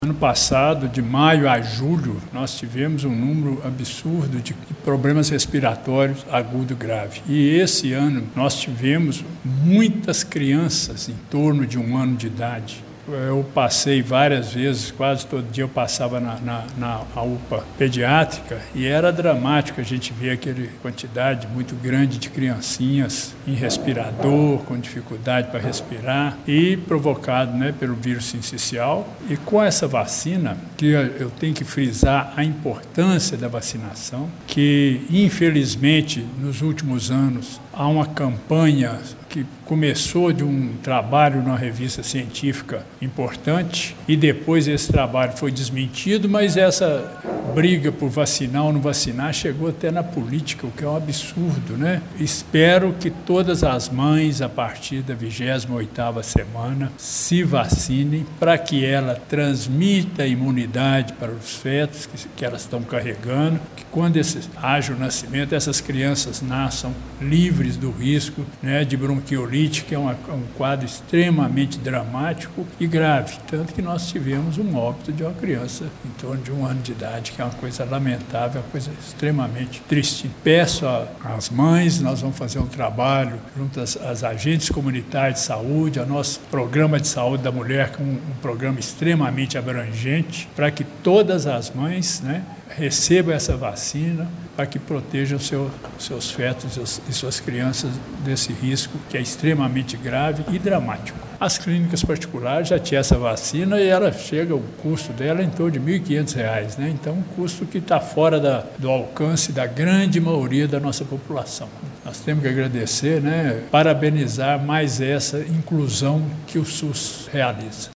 Gilberto reforça o apelo para que as gestantes procurem a vacinação e ressalta que agentes comunitários e equipes de saúde intensificarão ações para ampliar o alcance da campanha: